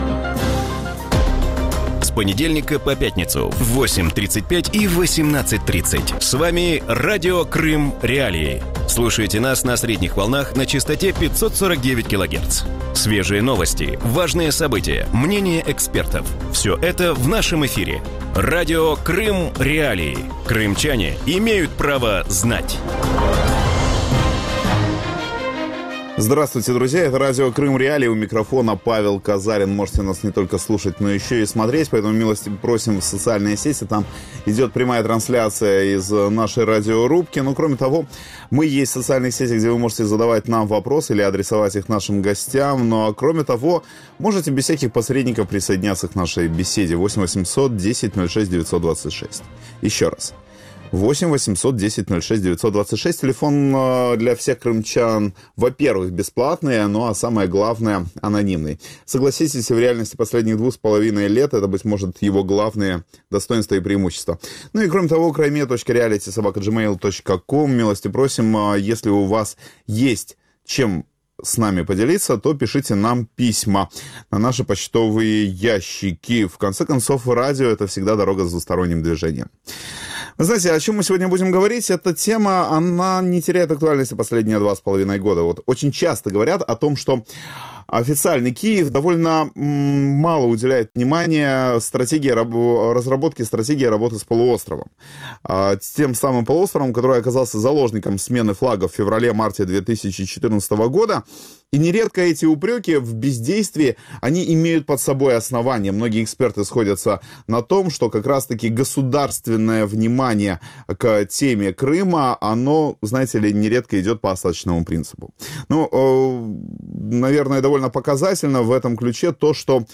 У вечірньому ефірі Радіо Крим.Реалії обговорюють ідею створення кримського уряду в екзилі. Чи допоможе створення Ради міністрів Автономної республіки Крим на материку деокупації півострова?